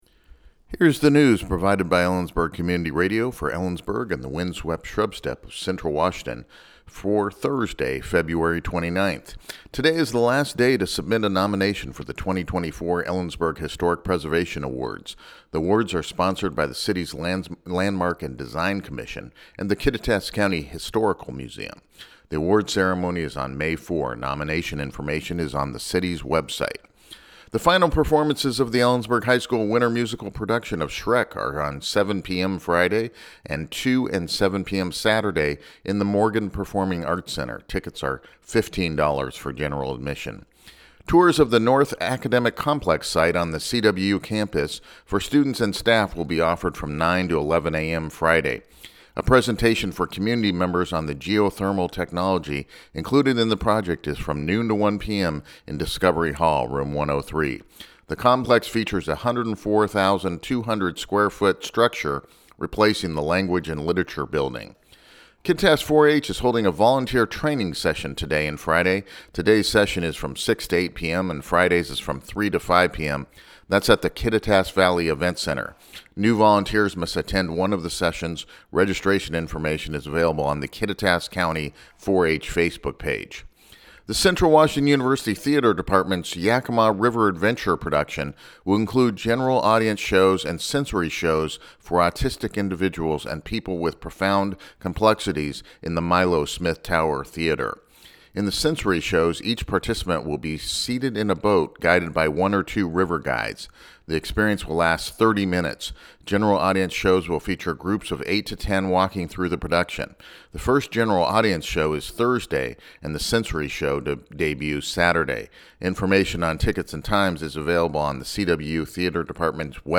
Photo courtesy of Ellensburg High School LISTEN TO THE NEWS HERE NEWS Last day to submit nominations Today is the last day to submit a nomination for the 2024 Ellensburg Historic Preservation Awards.